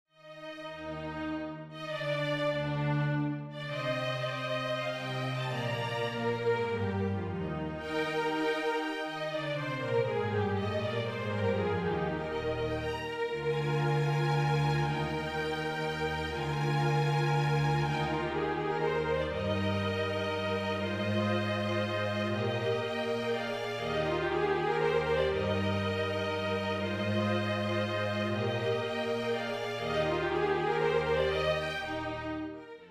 Singspiel
Orchester-Sound